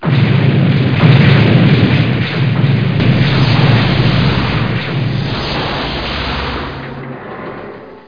EXPLODE5.mp3